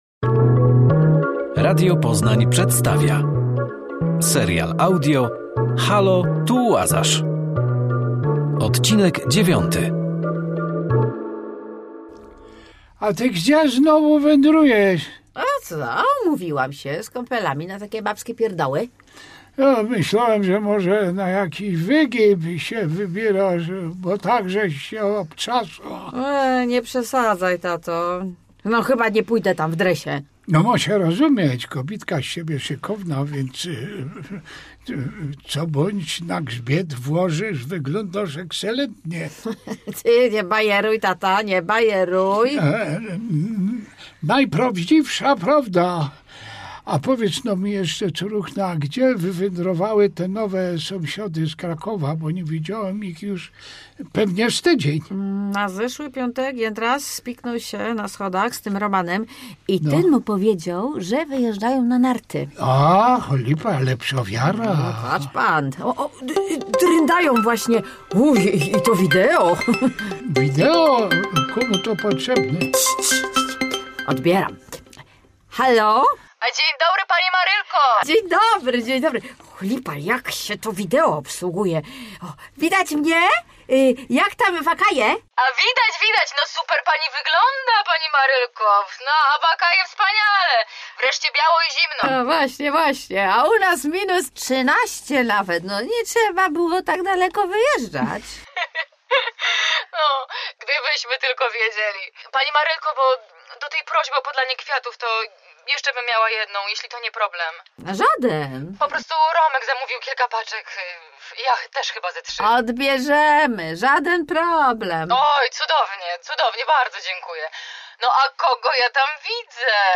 Serial audio Radia Poznań